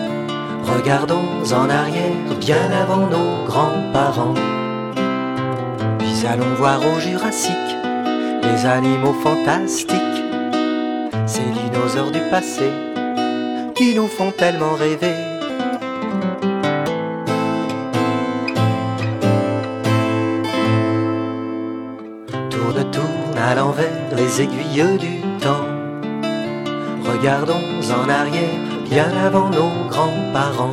Cet album propose un conte musical et un conte pédagogique.
Chanson